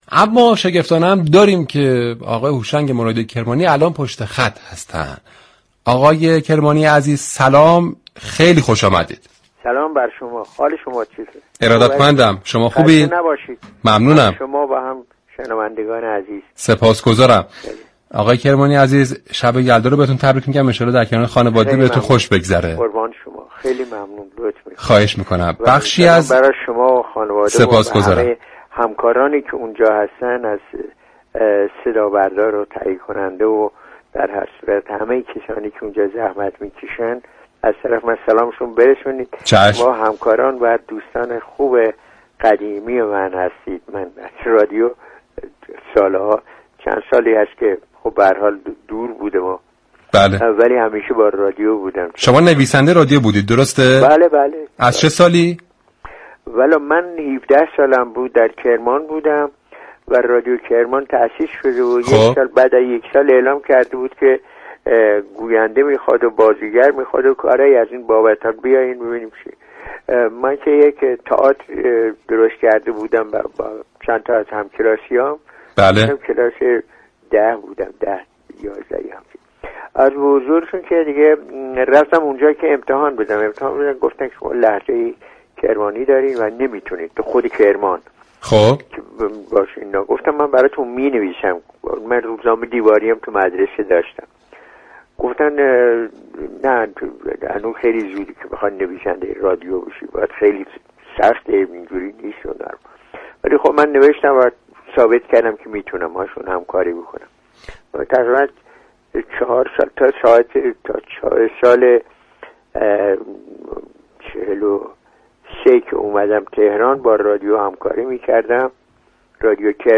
هوشنگ مرادی كرمانی در گفتگو با رادیو صبا درباره خلق شخیت مجید دركتاب قصه های مجید توضیح داد.
این چهره ماندگاركشوری سه شنبه 30آذر دربرنامه «همپای صبا » در رادیو صبا درباره آثارش گفتگو داشت.